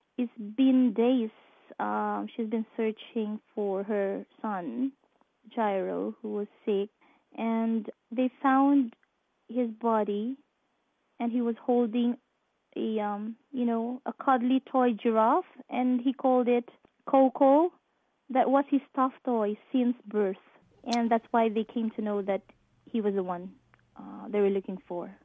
Heartbreaking BBC Essex audio from Harlow woman, close family friend of father and son, killed in Typhoon Haiyan